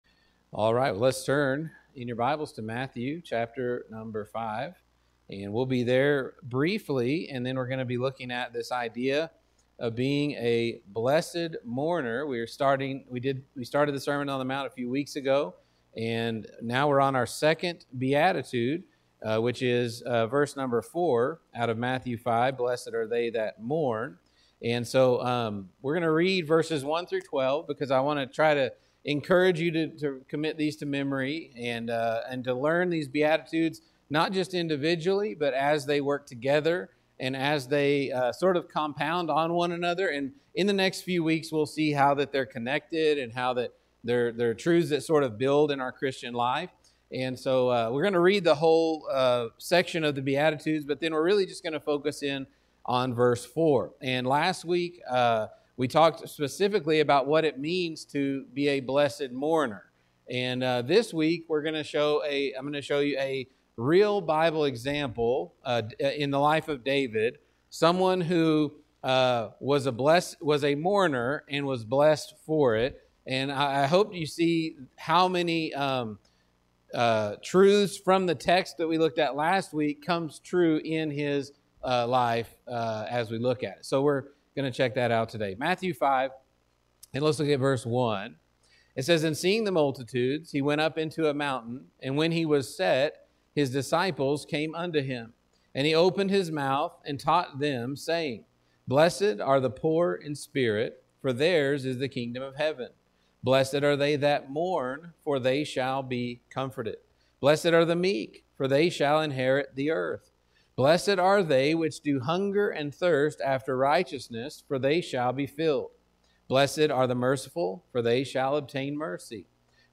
Sermon-on-the-Mount-The-Blessed-Mourner-Part-2.mp3